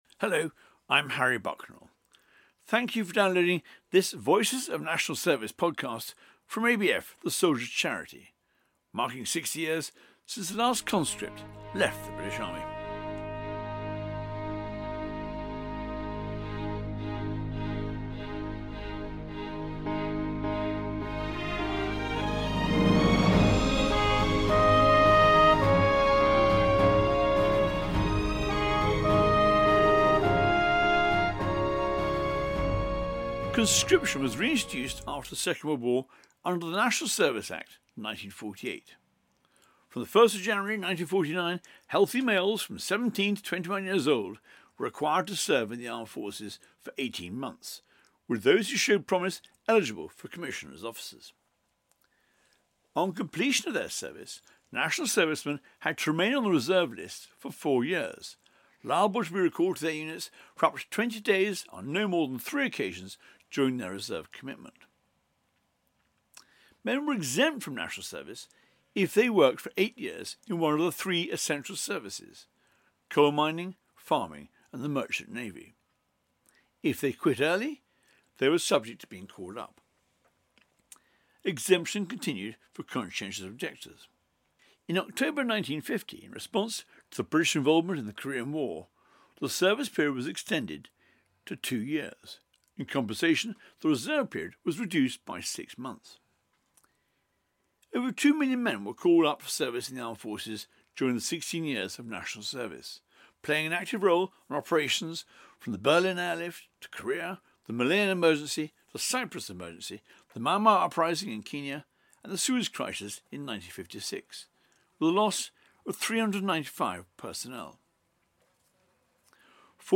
Army national service veterans